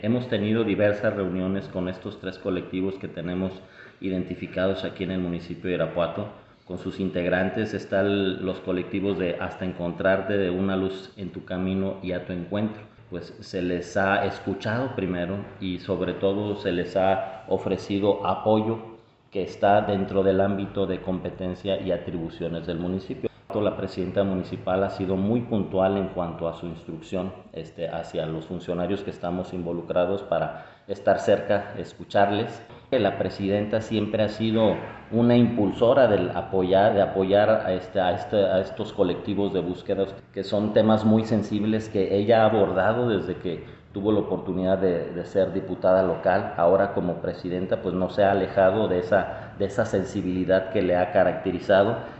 Rodolfo Gómez Cervantes, Secretario de Ayuntamiento